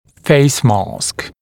[‘feɪsmɑːsk][‘фэйсма:ск]лицевая маска, реверсивная лицевая дуга